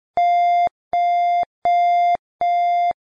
So klingt der Unterschied von zwei Dezibel (Tipp: Der erste Ton ist etwas lauter als der zweite):